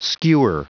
Prononciation du mot skewer en anglais (fichier audio)
Prononciation du mot : skewer